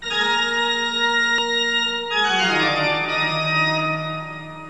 ORGAN.WAV